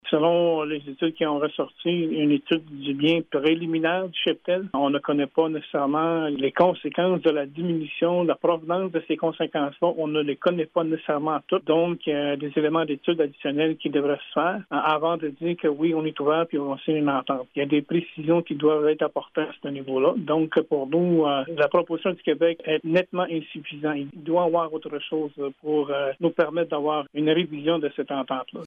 D’autres sujets ont été abordés tels que l’échéancier des négociations, qui semble trop court selon Lucien Wabanonik, négociateur, conseiller et membre de la communauté de lac Simon, explique :